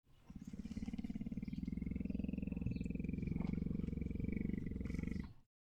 Lion Purr Sound
animal
Lion Purr